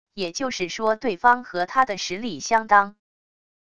也就是说对方和他的实力相当wav音频生成系统WAV Audio Player